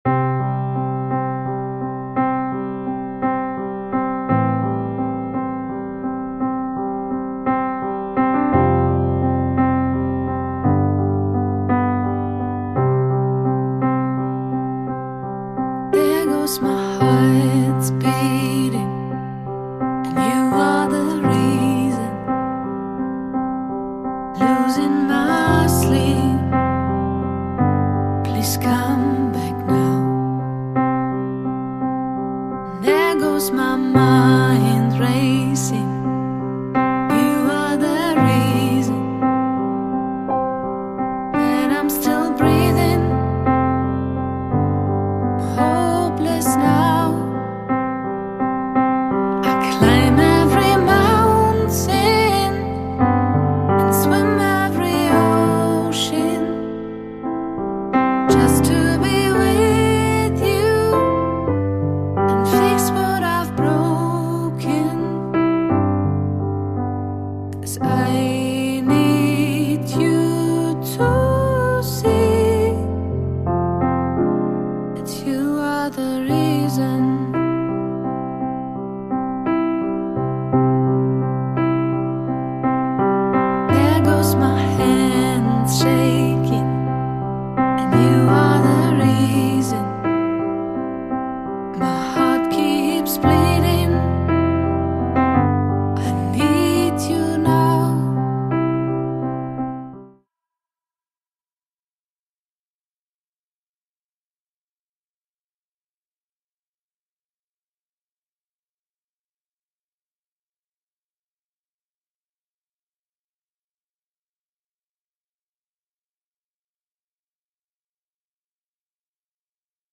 Gefühlvolle Balladen und mehr